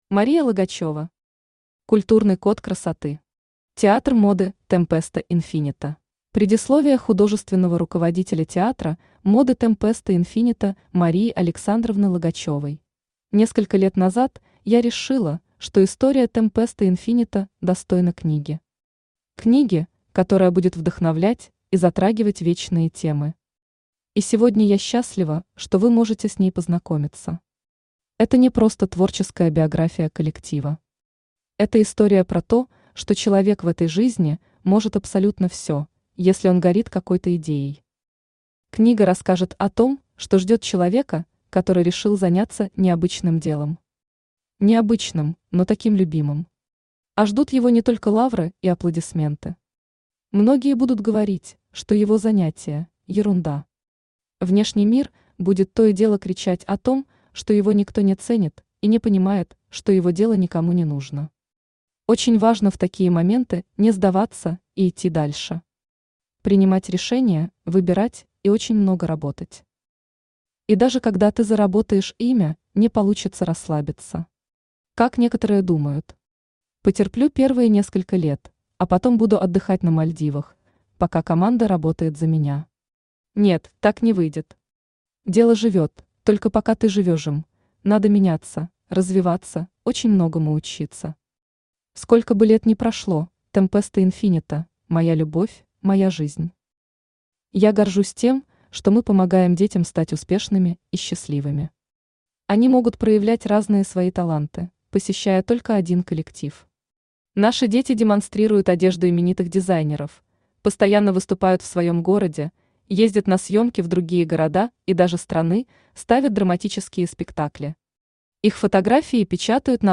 Театр моды Tempesta infinito Автор Мария Логачёва Читает аудиокнигу Авточтец ЛитРес.